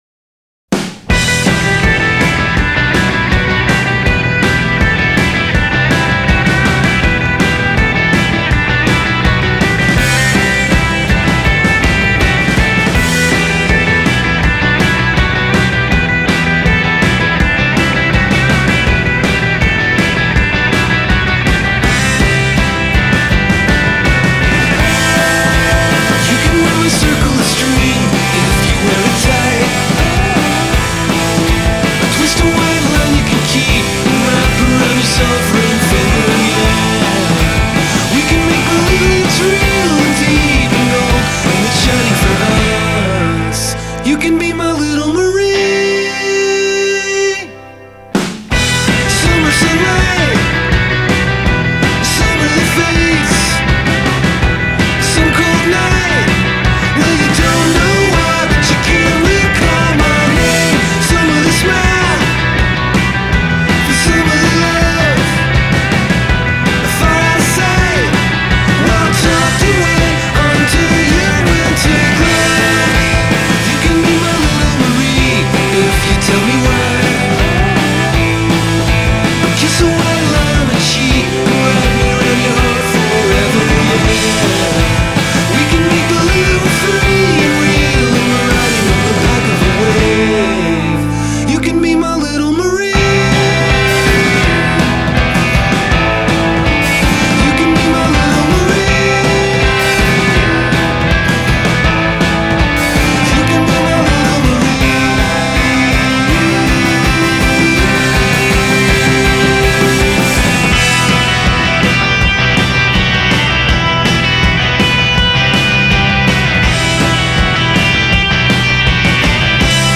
a track that bolts out of the gate and doesn’t stop